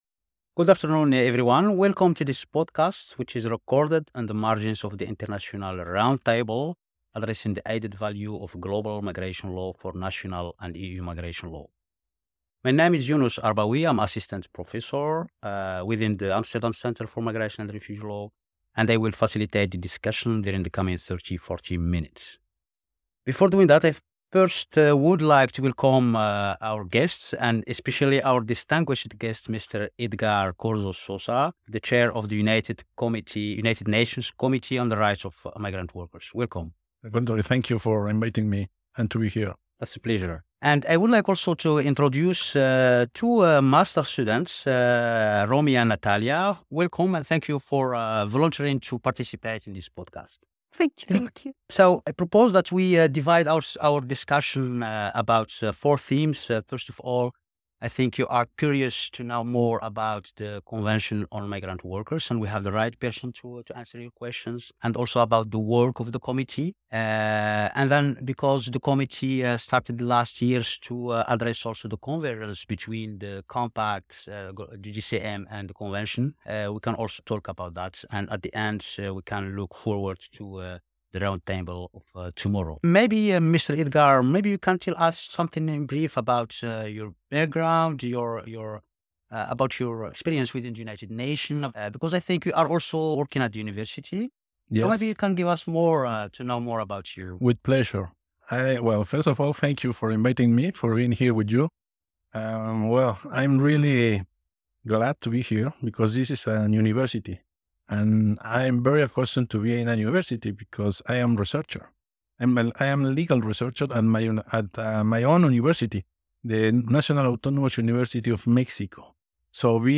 Podcast discussion with Edgar Corzo Sosa, a committee member of the Committee on Migrant Workers, concerning the International Convention on Migrant Workers (CMW), the Committee for Migrant Workers, and its partnership with the Global Compact for Migration (GCM).
An interview with Dr. Edgar Corzo.mp3